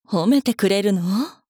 大人女性│女魔導師│リアクションボイス│商用利用可 フリーボイス素材 - freevoice4creators
照れる